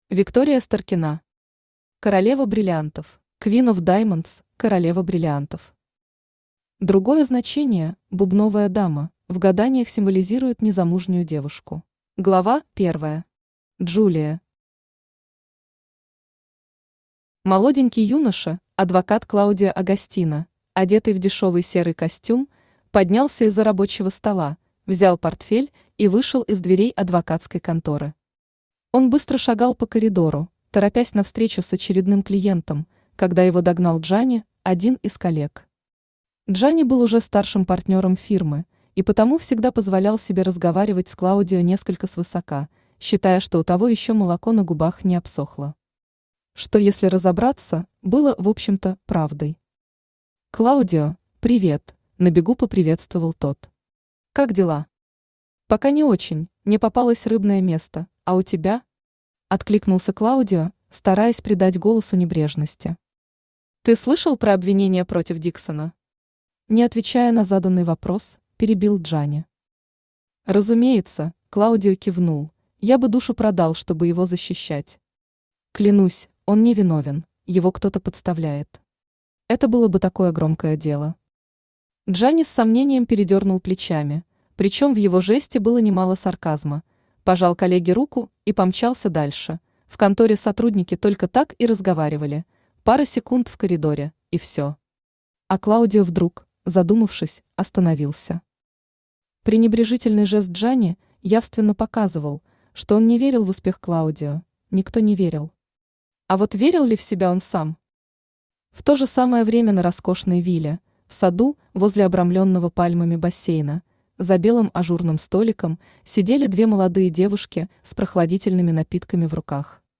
Аудиокнига Королева бриллиантов | Библиотека аудиокниг
Aудиокнига Королева бриллиантов Автор Виктория Старкина Читает аудиокнигу Авточтец ЛитРес.